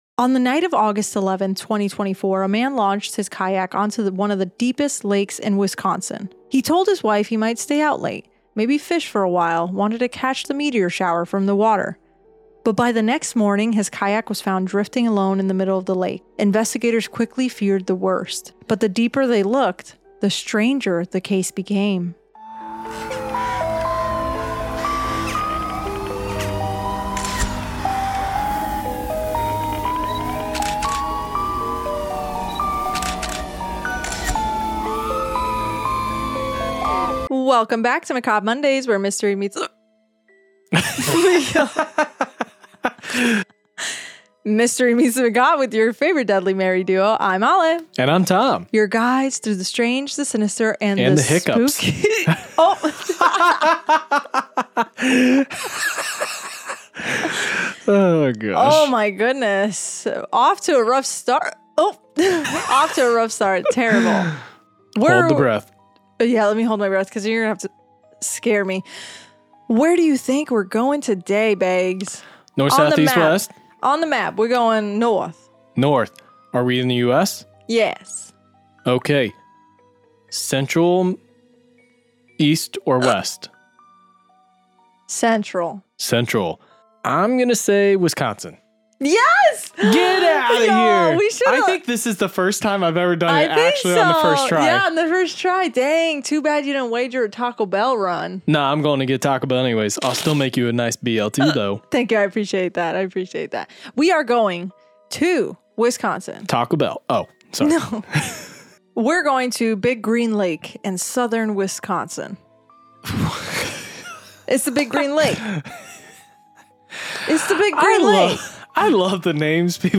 true crime and paranormal podcast